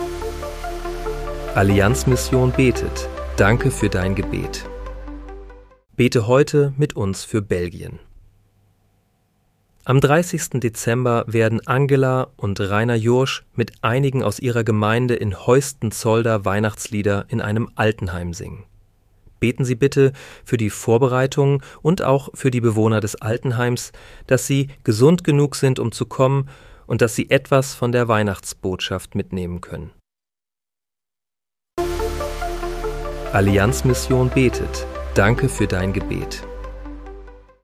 Bete am 30. Dezember 2025 mit uns für Belgien. (KI-generiert mit